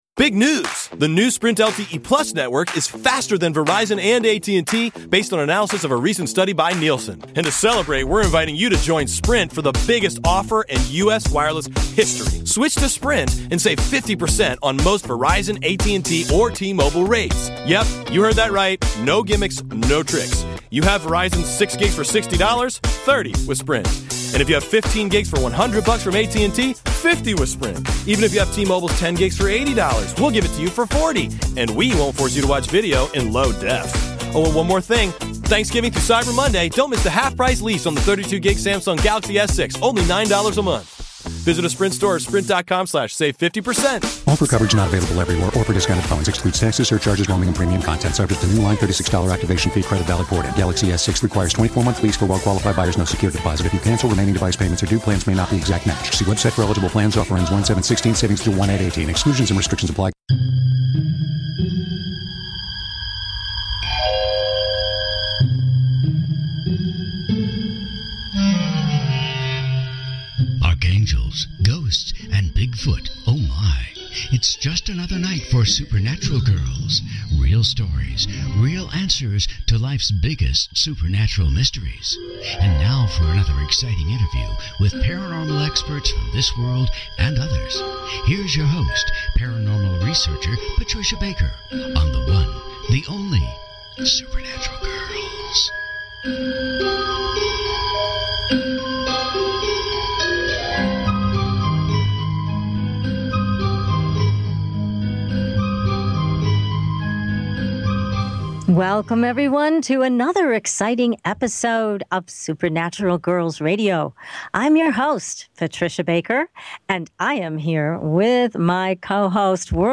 Supernatural Girlz Radio Interview | The Accidental Prophet